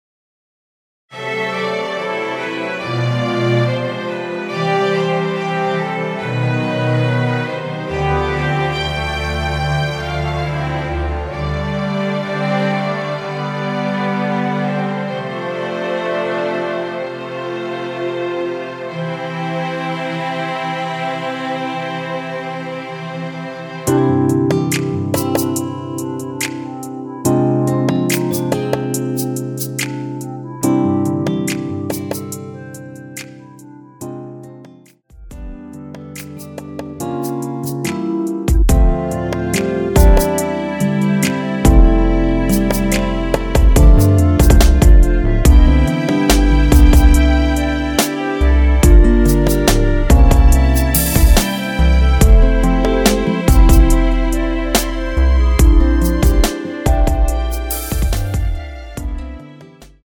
원키에서(-4)내린 멜로디 포함된 MR입니다.
주 멜로디만 제작되어 있으며 화음 라인 멜로디는 포함되어 있지 않습니다.(미리듣기 참조)
앞부분30초, 뒷부분30초씩 편집해서 올려 드리고 있습니다.
중간에 음이 끈어지고 다시 나오는 이유는